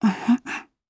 Gremory-Vox_Happy4_jp.wav